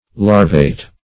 Search Result for " larvate" : The Collaborative International Dictionary of English v.0.48: Larvate \Lar"vate\, a. [L. larva mask.]
larvate.mp3